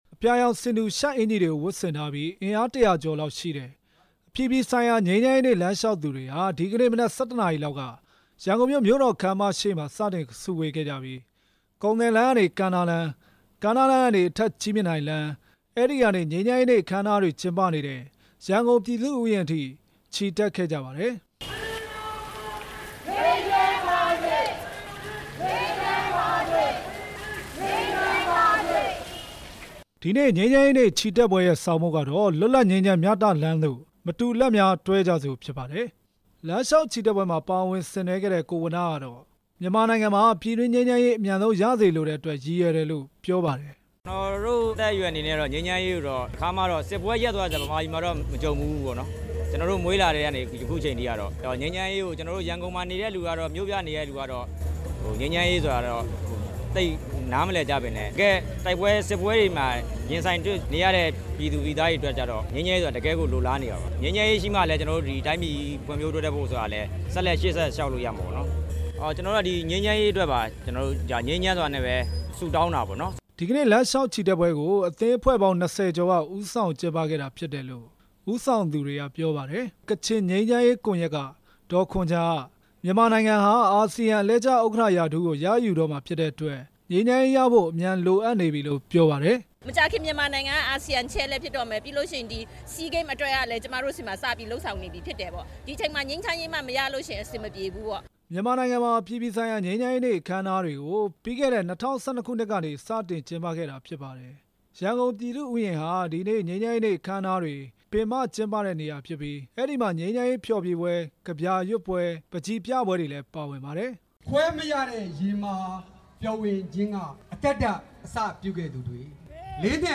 ရန်ကုန်မှာကျင်းပတဲ့ အပြည်ပြည်ဆိုင်ရာ ငြိမ်းချမ်းရေးနေ့ အခမ်းအနားများ တင်ပြချက်